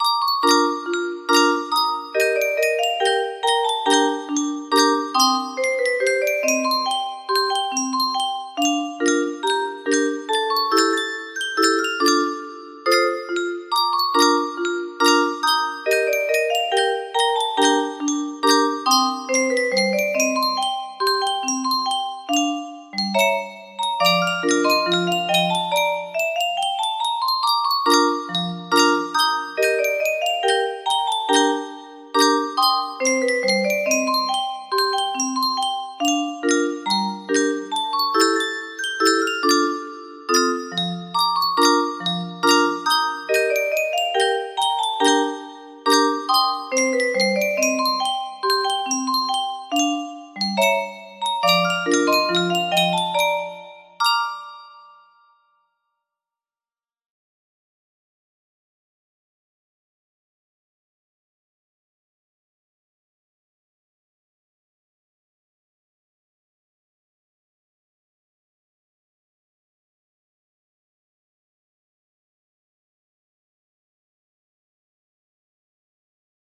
The Entertainer (The Middle Part) - Scott Joplin music box melody
Grand Illusions 30 (F scale)